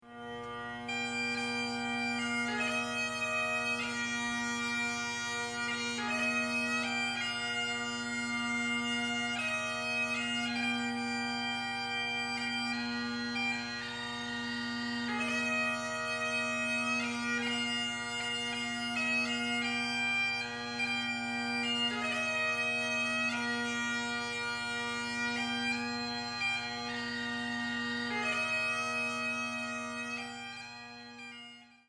Bagpipes 499k)